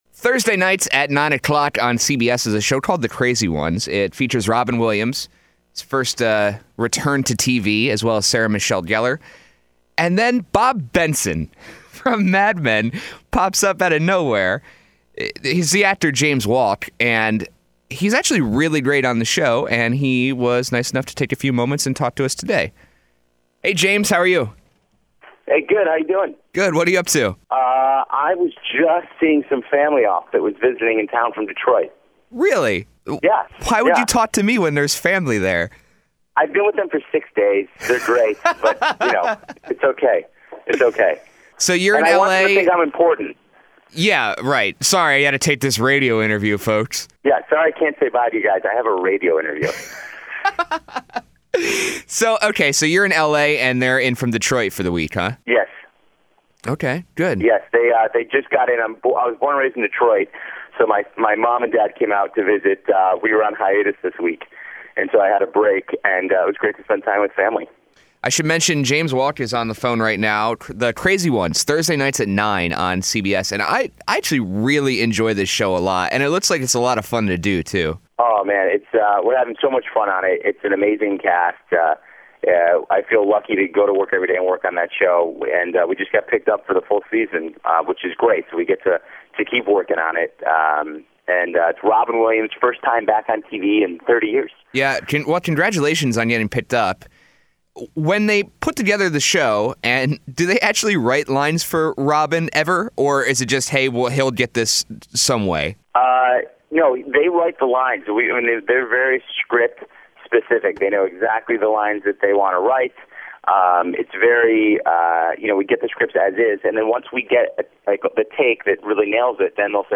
Interview: James Wolk, Bob Benson Of ‘Mad Men’
james-wolk-interview.mp3